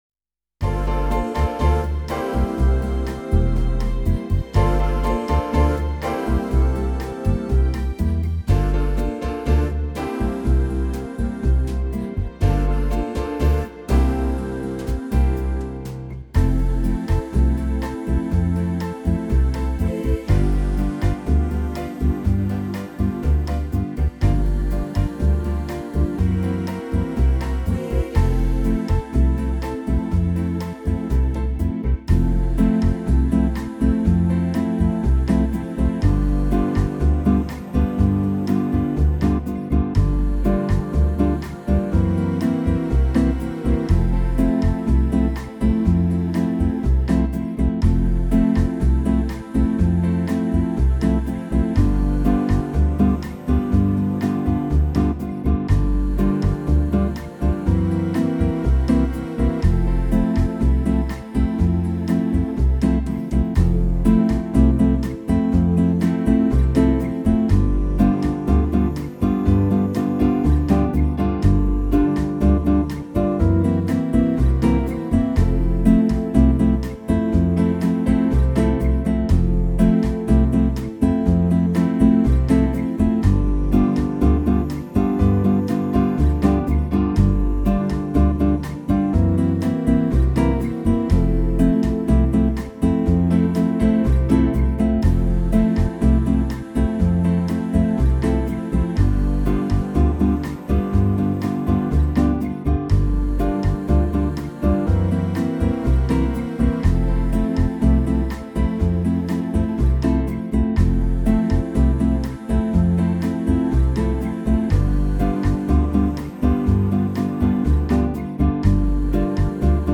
Home > Music > Jazz > Bright > Smooth > Medium